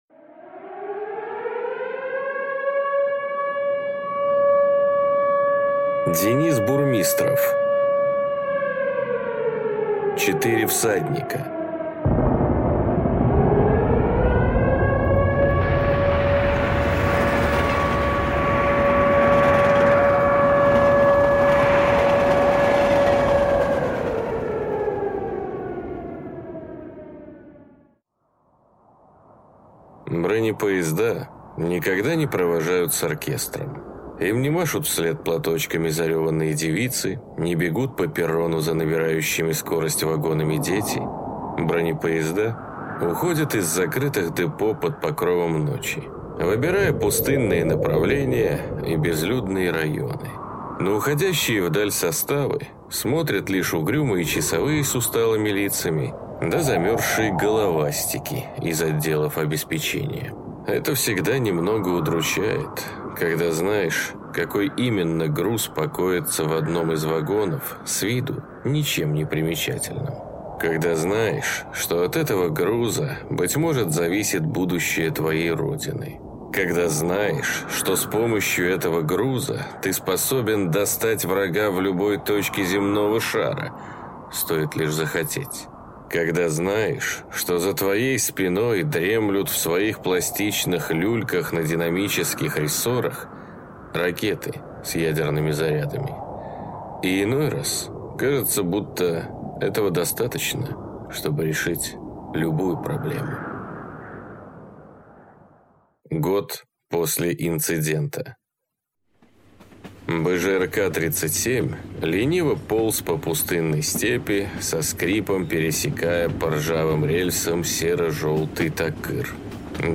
Аудиокнига Четыре всадника | Библиотека аудиокниг